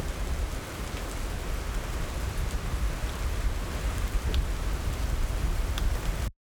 Index of /audio/samples/SFX/IRL Recorded/Rain - Thunder/
Rain With Wind.wav